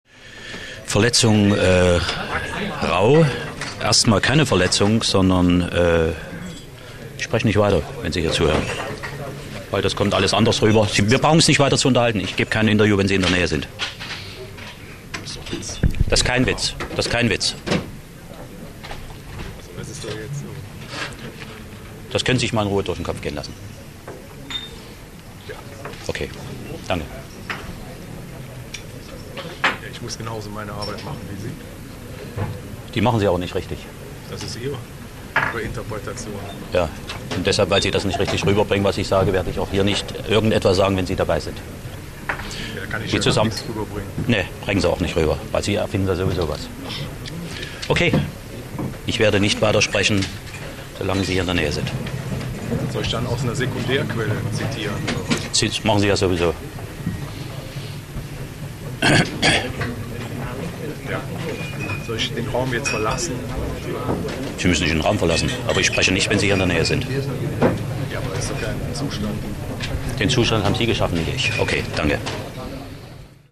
Mitschnitt aus einem Interview